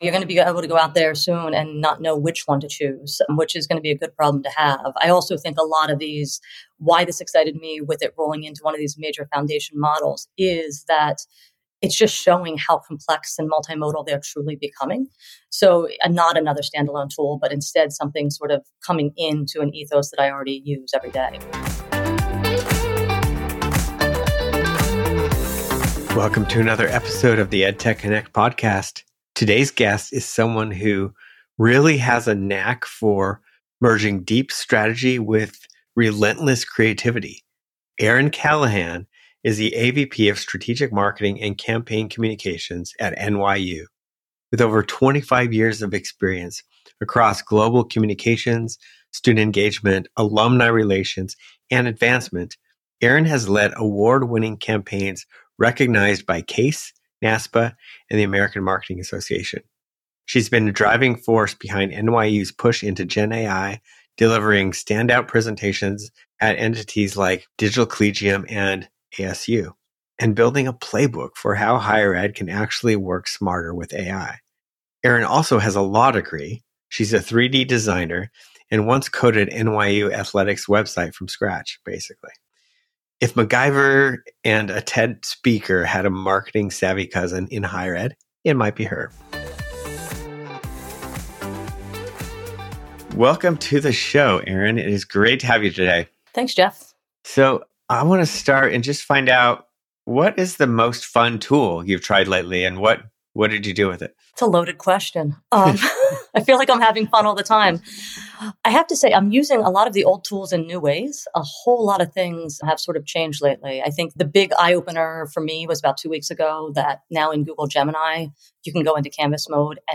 Each episode features interviews with leading experts, educators, technologists and solution providers, who share their insights on how technology can be used to improve student engagement, enhance learning outcomes, and transform the educational experience.